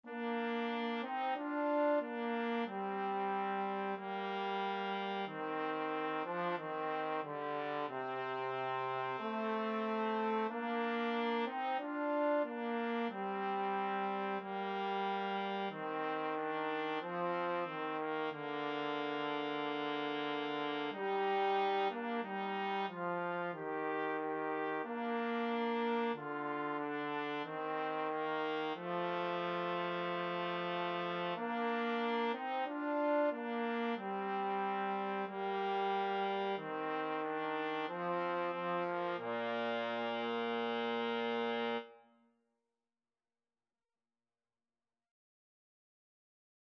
Andante = c. 92
4/4 (View more 4/4 Music)
Classical (View more Classical Trumpet-Trombone Duet Music)